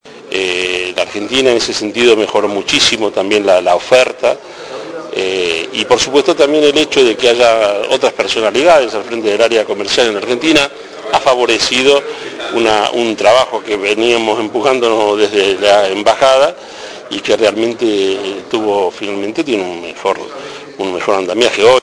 En entrevista con Espectador al día, el diplomático sostuvo que el balance de la recomposición de relaciones es positivo, porque él llegó en momentos en donde no se hablaban prácticamente entre las dos partes.